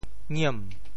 “儼”字用潮州话怎么说？
ngiem2.mp3